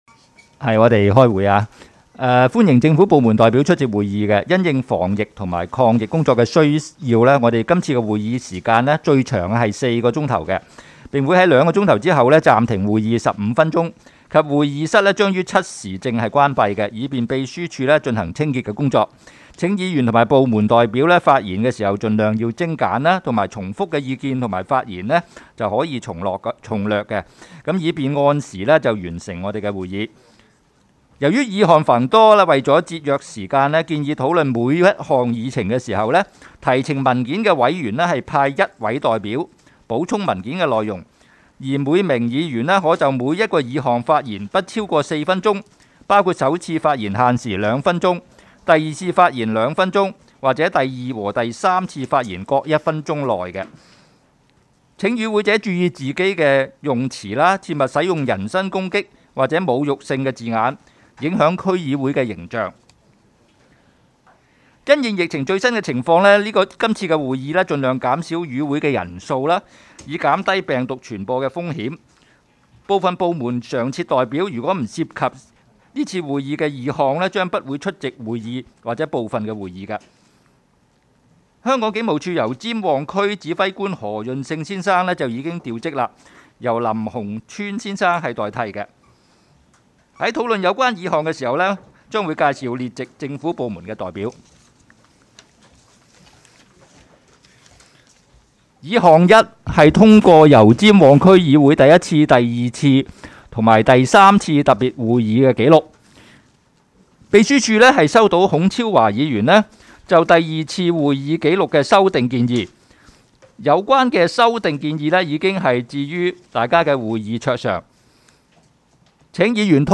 区议会大会的录音记录
地点: 九龙旺角联运街30号 旺角政府合署4楼 油尖旺区议会会议室